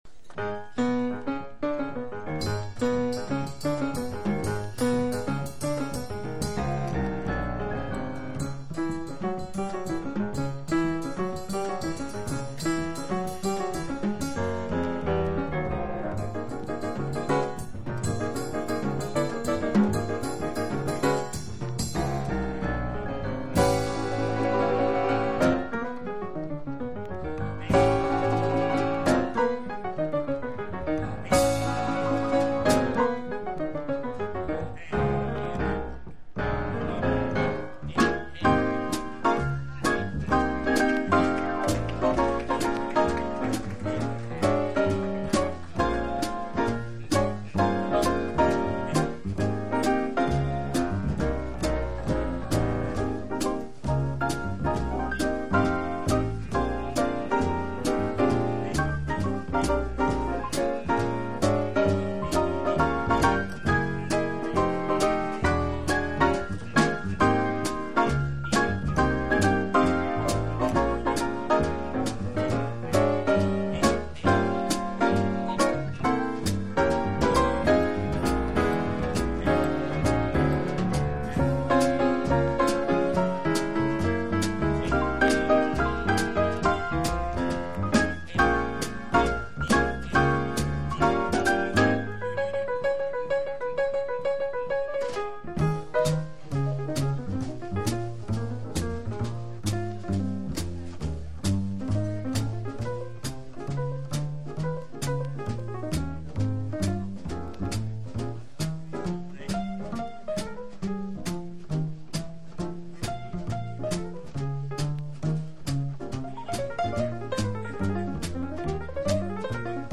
（MONO針で聴くとほとんどノイズでません）※曲…